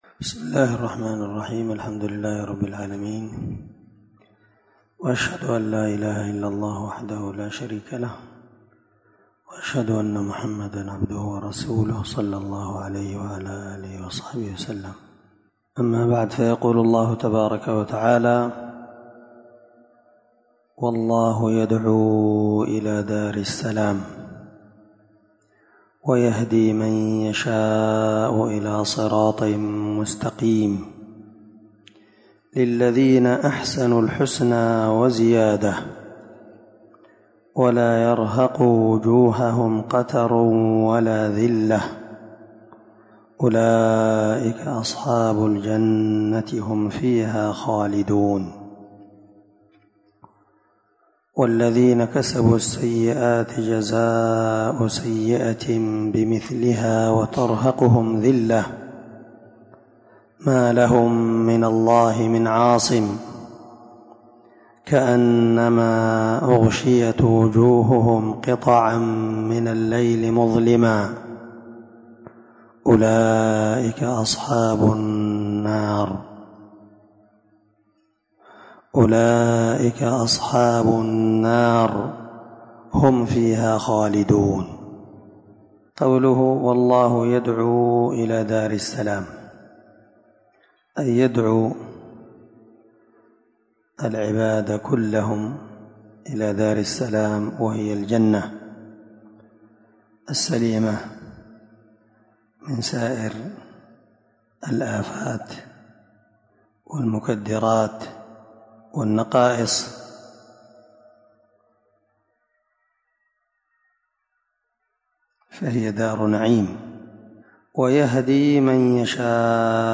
595الدرس 11 تفسير آية ( 25- 27) من سورة يونس من تفسير القران الكريم مع قراءة لتفسير السعدي
دار الحديث- المَحاوِلة- الصبيحة.